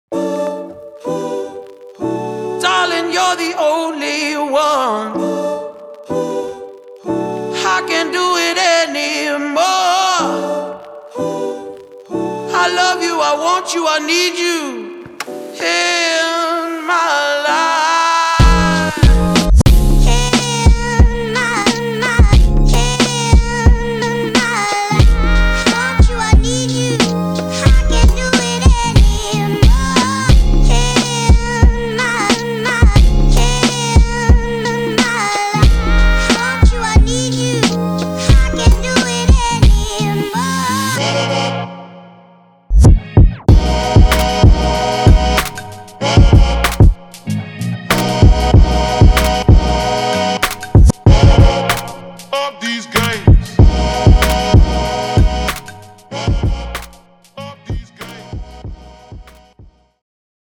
Soul
The demo features drum sounds